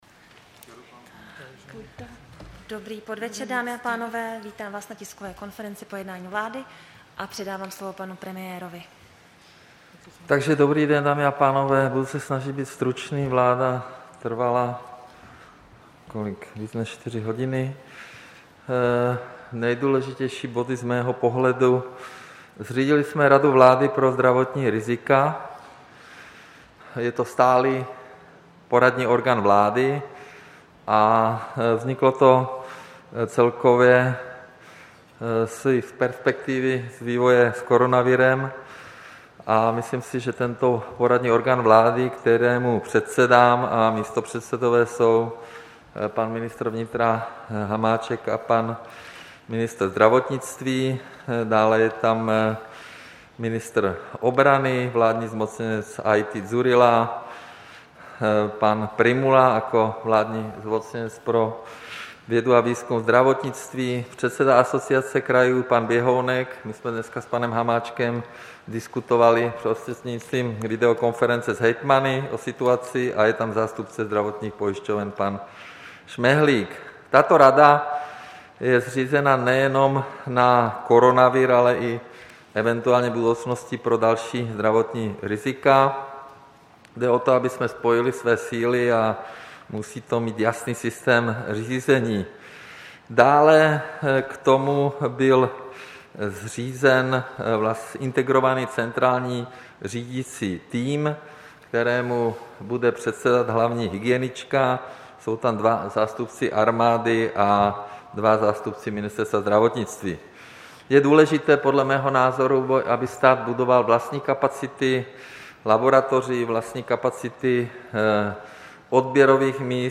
Tisková konference po jednání vlády, 27. července 2020